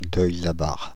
Deuil-la-Barre (French pronunciation: [dœj la baʁ]
Fr-Paris--Deuil-la-Barre.ogg.mp3